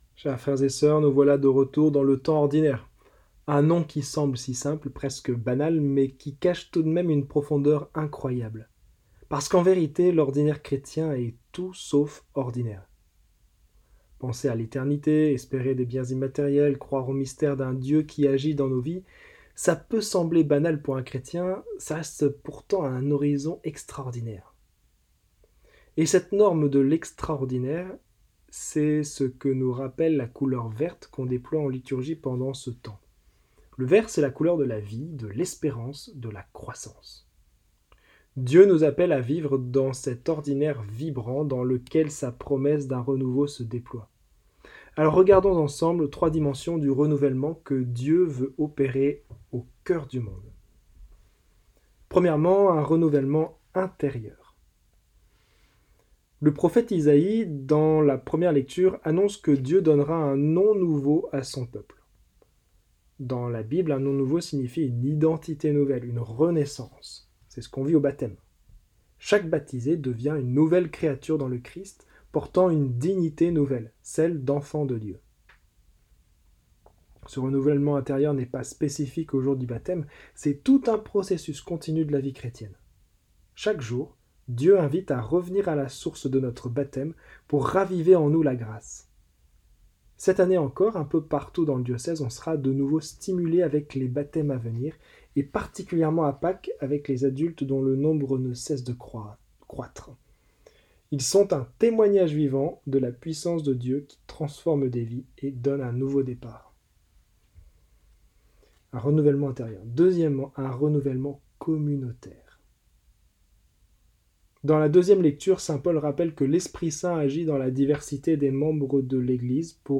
Homélies en 3 points